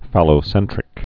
(fălō-sĕntrĭk)